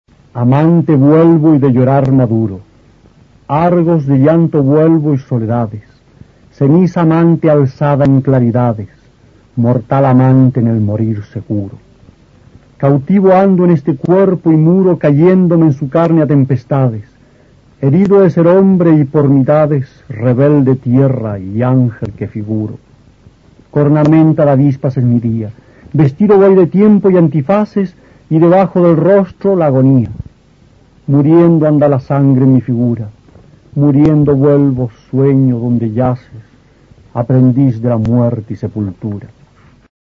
Aquí se puede escuchar al poeta chileno Roque Esteban Scarpa (1914-1995) recitando uno de sus sonetos.
Poema